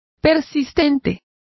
Complete with pronunciation of the translation of lingering.